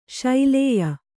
♪ śailēya